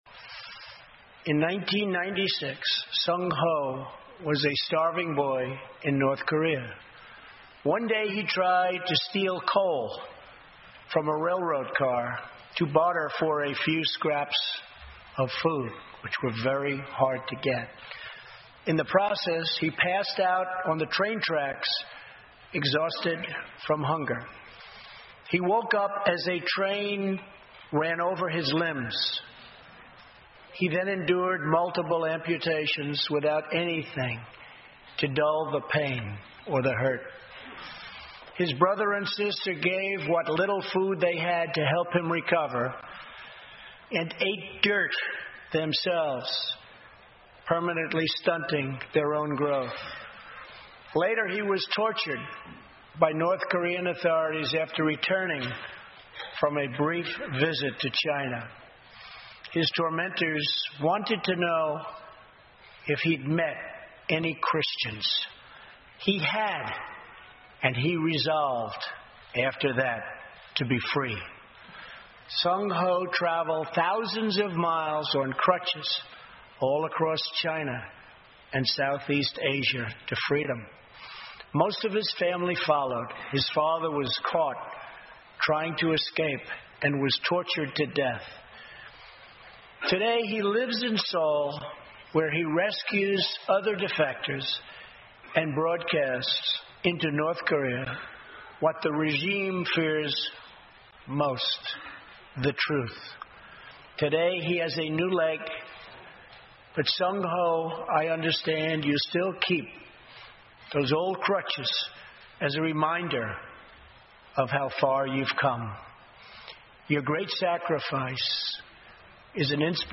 欧美名人演讲 第117期:美国总统川普首次国情咨文演讲(24) 听力文件下载—在线英语听力室